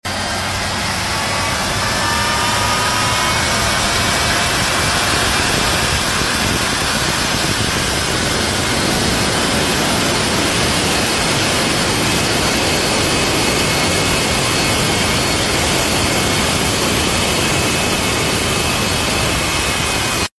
Jet Powered Pontoon Boat do sound effects free download